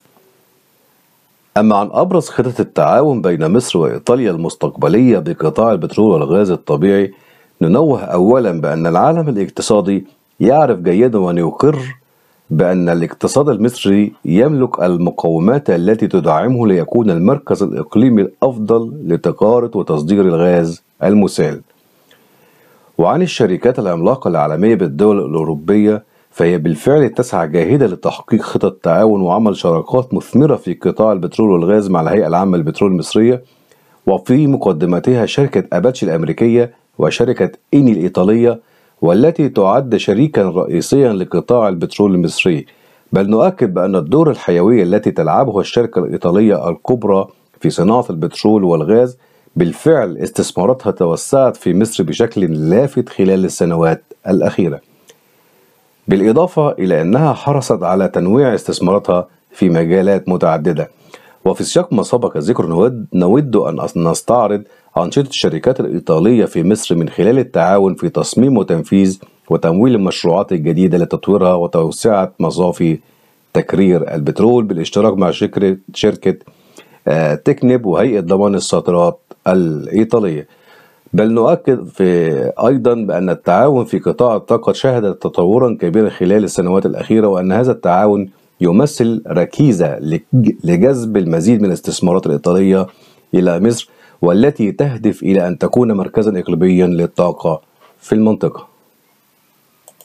محلل اقتصادي